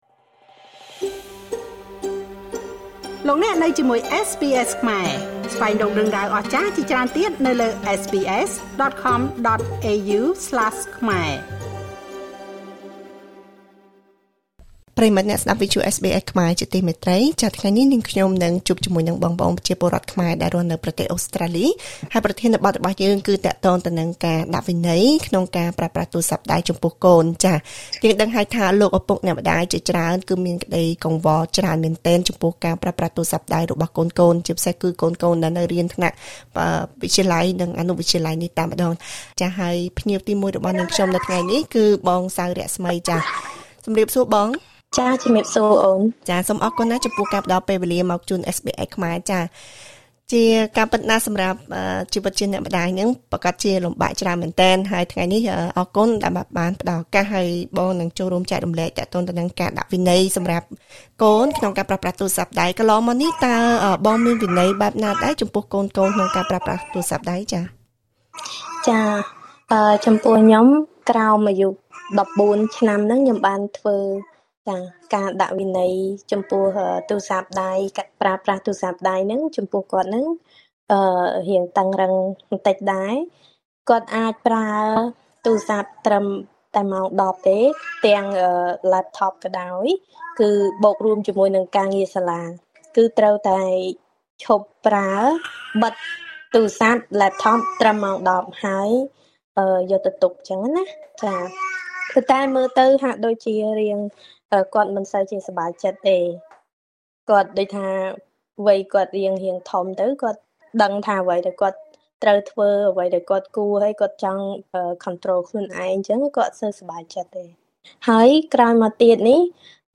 ឪពុកម្តាយជាច្រើនរូបតែងមានក្តីព្រួយបារម្ភចំពោះការចំណាយពេលនៅលើកញ្ចក់អេក្រង់ ក៏ដូចជាការប្រើប្រាស់ទូរសព្ទដៃរបស់កូនៗ ពីព្រោះការប្រើប្រាស់ដែលគ្មានវិន័យត្រឹមត្រូវ វាធ្វើឲ្យប៉ះពាល់ដល់សុខភាពនិងការសិក្សា។ សូមស្តាប់បទសម្ភាសន៍ជាមួយប្រពលរដ្ឋខ្មែរអូស្រ្តាលីដែលនឹងចែករំលែកអំពីពិសោធន៍ក្នុងការដាក់វិន័យចំពោះកូនៗពាក់ព័ន្ធនឹងបញ្ហានេះ។